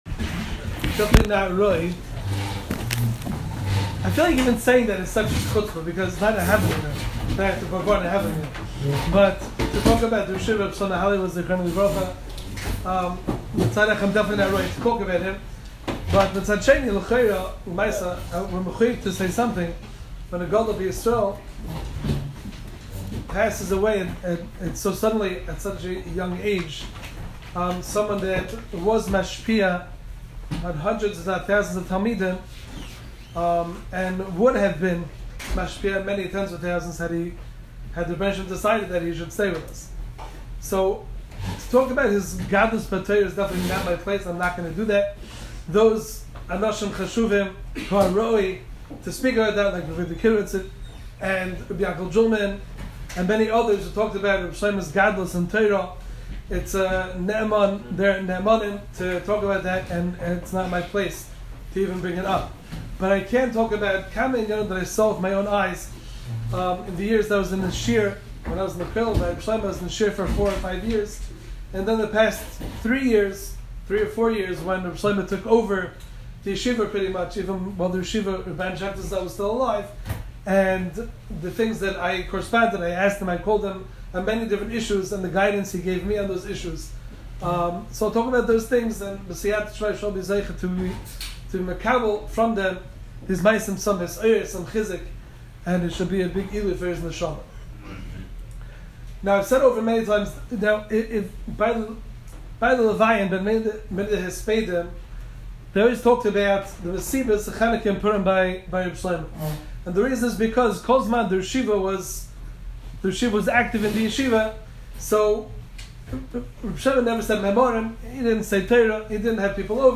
Hespedim